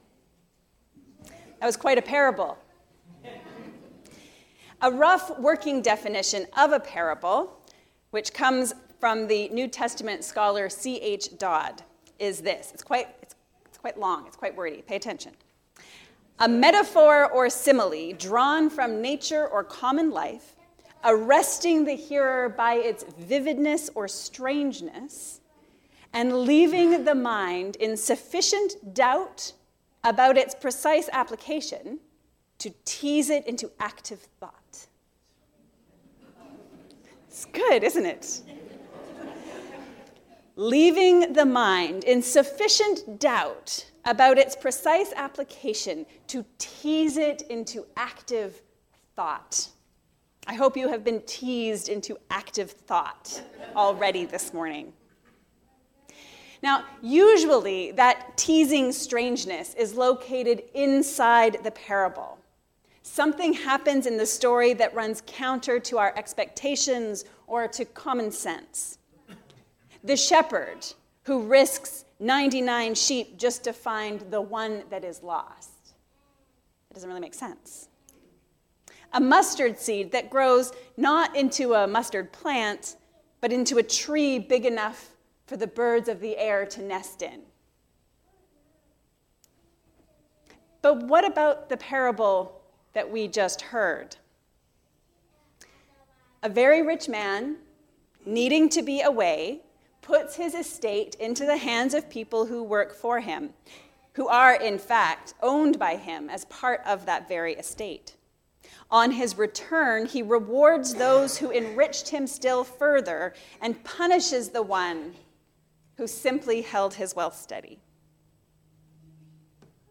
A bold kind of waiting. A sermon on Matthew 25:14-50